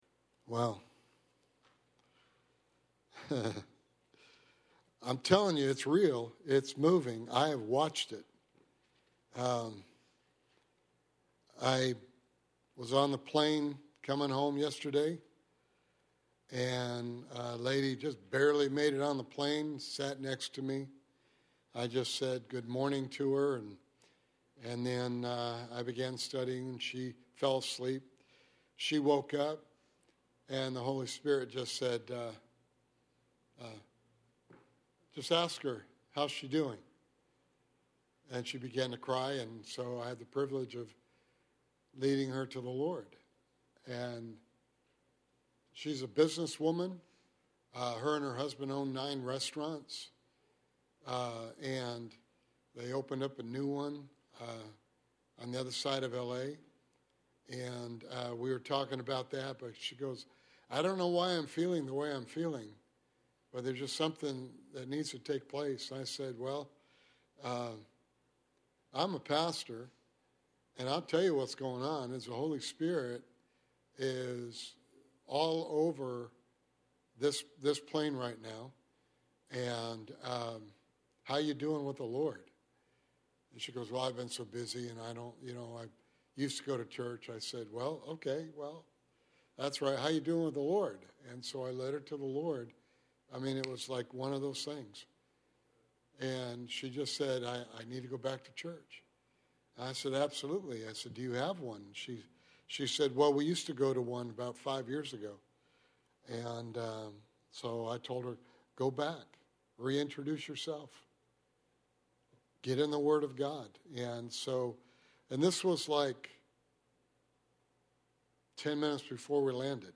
Sermon Series: The Plan of God (Bible Study)